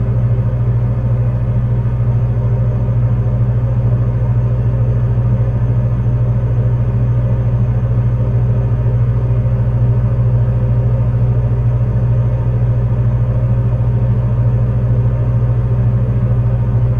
bet-locomotive.ogg